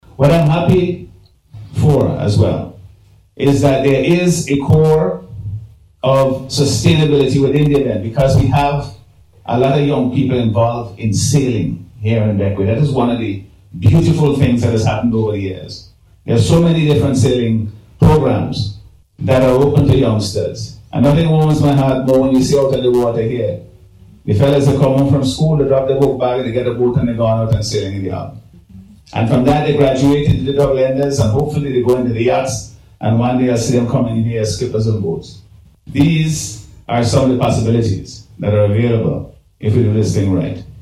He made this statement during Monday’s official launch of Sailing Week 2026, which runs from March 29th to April 6th.